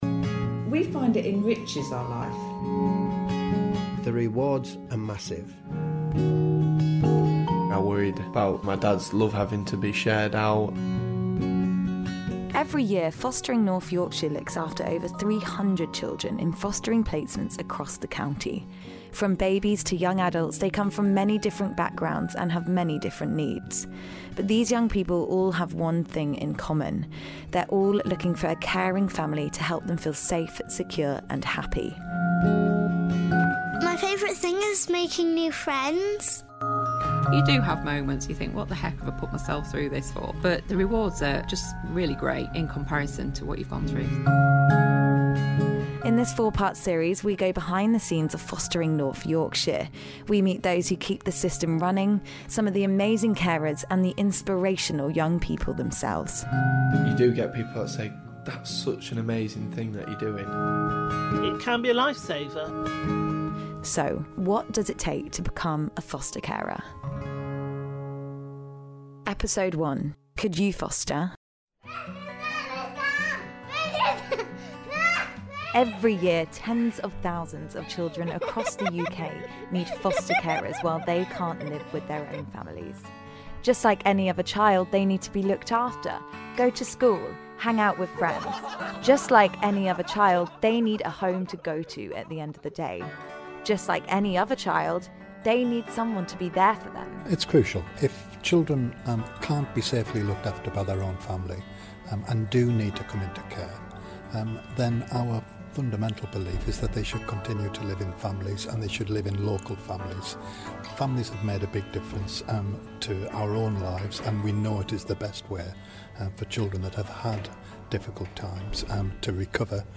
In the first of a four part documentary, Stray FM and North Yorkshire County Council come together to meet some of those involved.